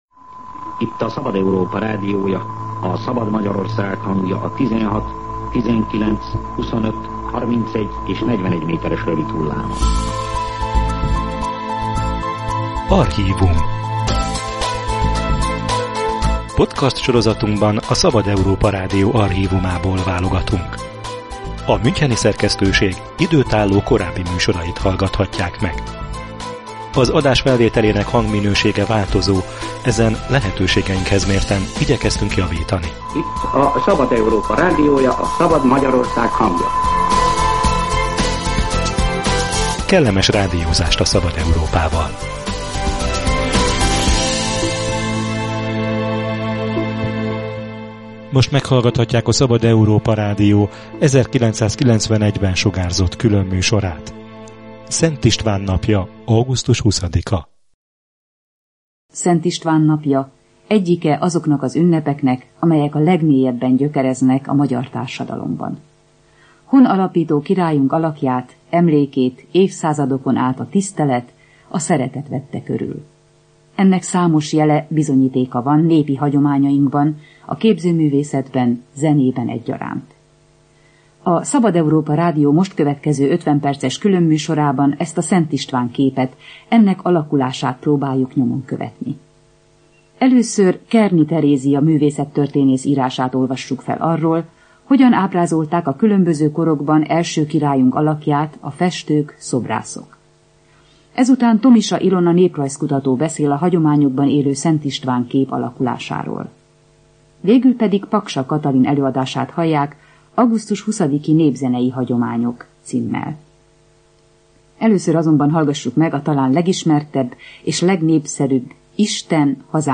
A tűzijáték elhalasztása apropóján sorozatunk is marad Szent István ünnepének témájánál: az 1991-es műsor művészettörténeti és néprajzi áttekintést ad a király alakjáról, augusztus 20. és a kenyér szimbólum kapcsolatáról. Felcsendülnek több mint öt évtizede rögzített egyházi énekek is.